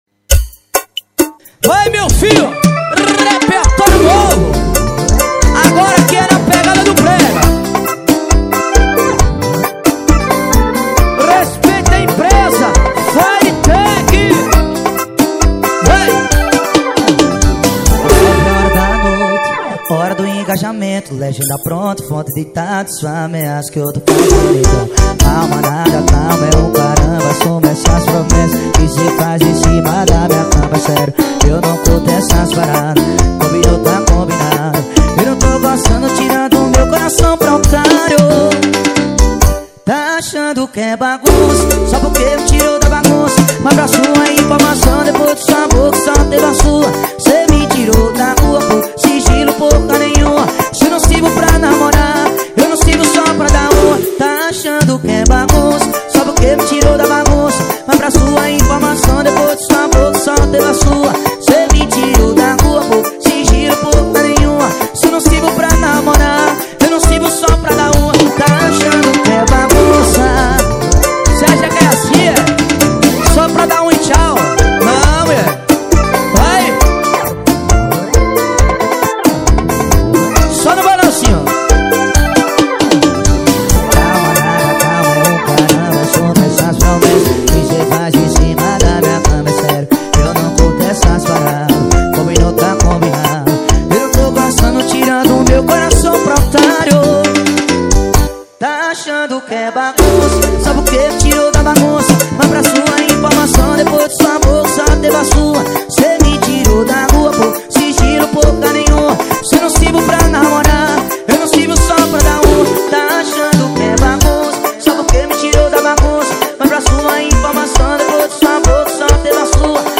2024-12-23 19:05:15 Gênero: Arrocha Views